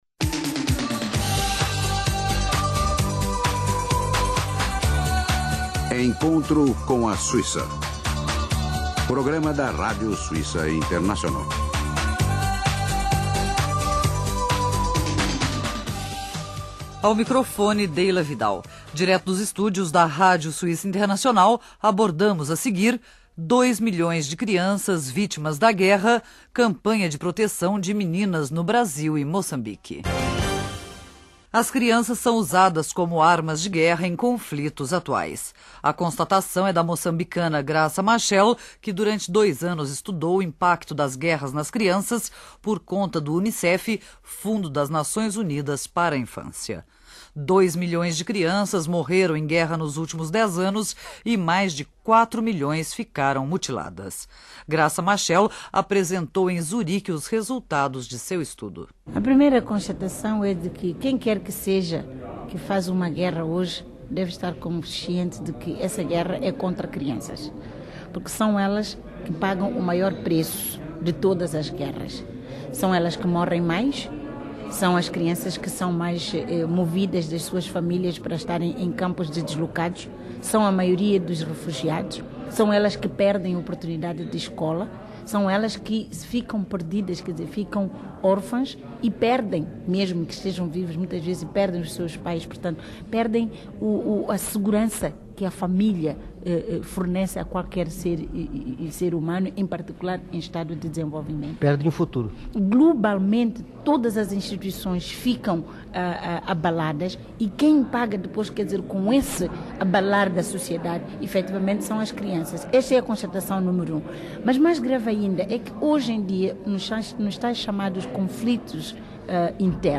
Entrevista exclusiva da Rádio Suíça Internacional com a esposa de Nelson Mandela, a moçambicana Graça Machel, sobre as crianças soldados em guerras na África (dezembro de 1996; duração: 3'37).